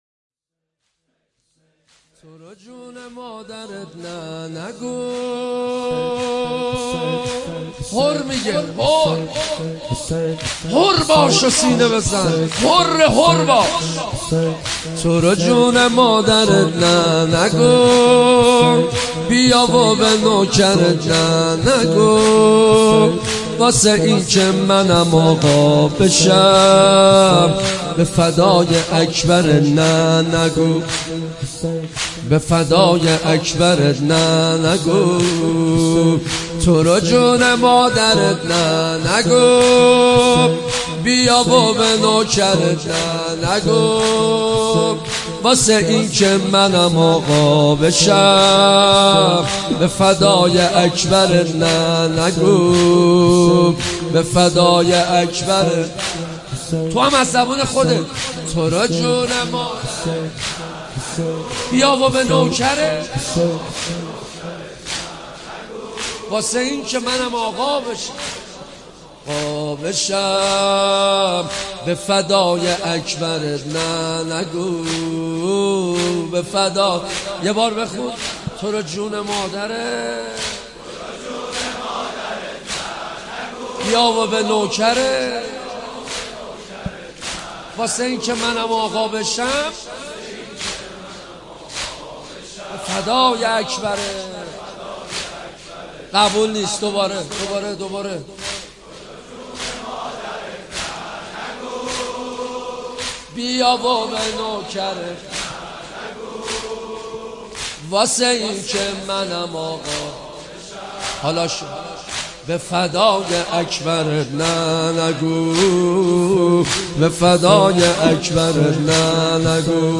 نوحه جديد
مداحی صوتی